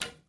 Shot.wav